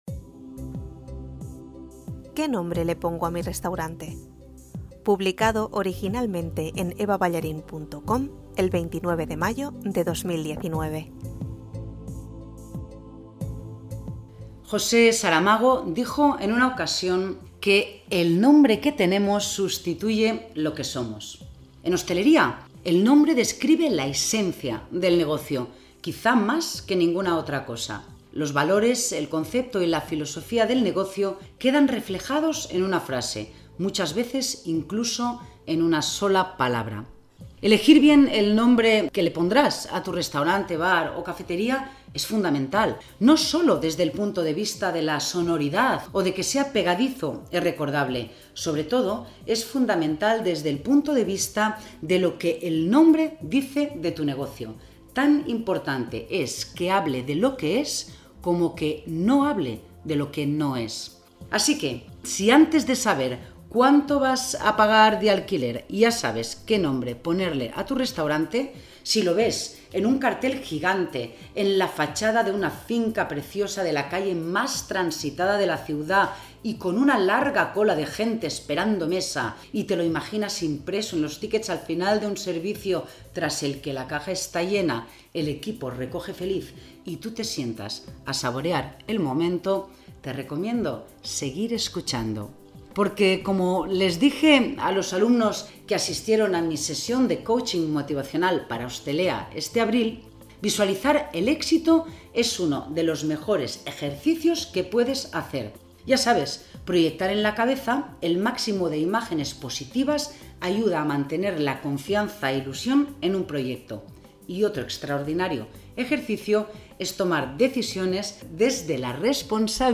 Si lo prefieres, te lo leo